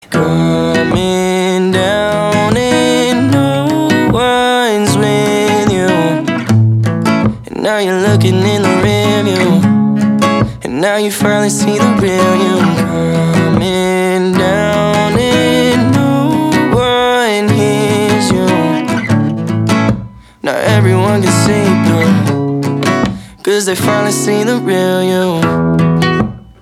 поп
гитара , спокойные , грустные , красивый мужской голос